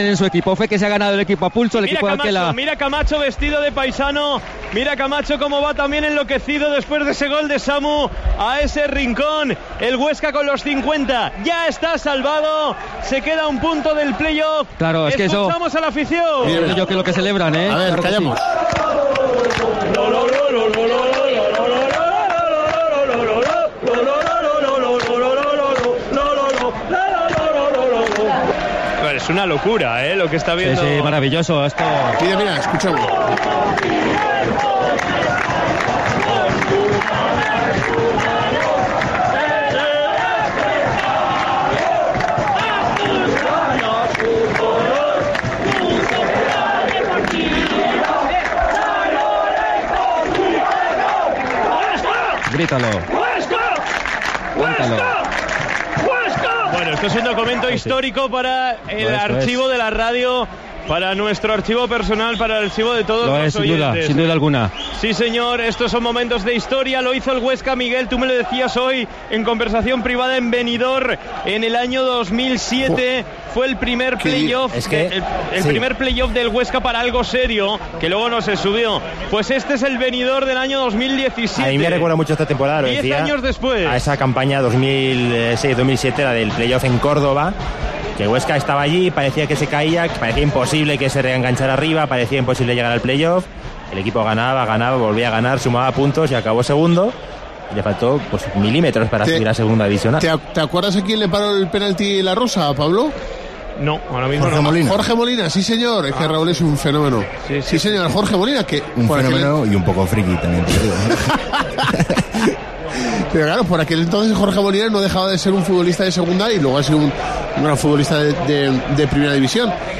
Escuchamos a los aficionados de la SD Huesca celebrar la victoria en Reus
Nada más finalizar el partido contra el Reus conseguimos escuchar la celebración de los 400 aficionados que acompañaron a la Sociedad Deportiva Huesca en este desplazamiento.
Escuchamos la celebración del triunfo en Reus